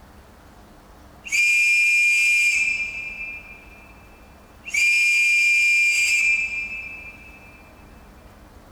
I believe there is nothing inherently wrong with the Perry whistle, or the sound emitted, however these days it is only moderate in performance when judged against a couple of the others looked at here.
Perry Whistle
perry-whistle.wav